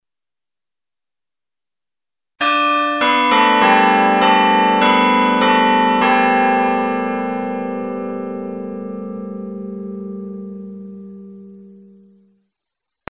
The Style E1002 Digital Chime system provides amplified audio output of a variety of digital chimes through a digital sound card and amplifier.
Chime Samples